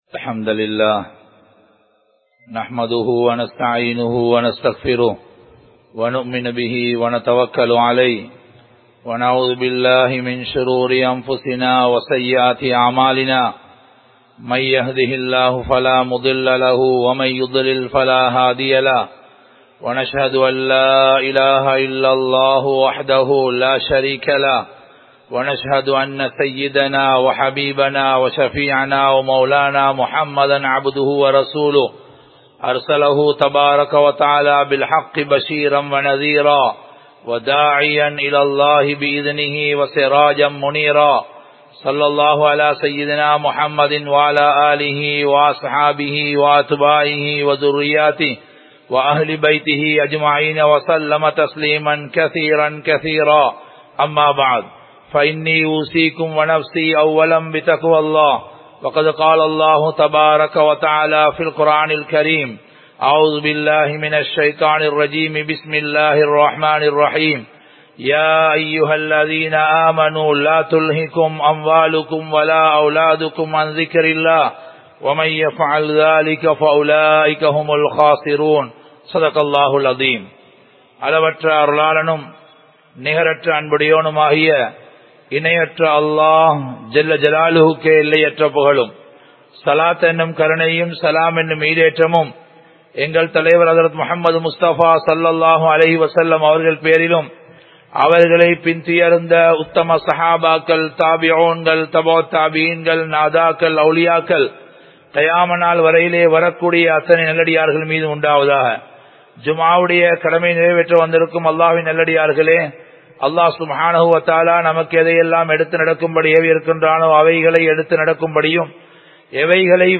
முஹர்ரமும் இன்றைய மனிதர்களின் நிலைமையும் | Audio Bayans | All Ceylon Muslim Youth Community | Addalaichenai
Muhiyaddeen Grand Jumua Masjith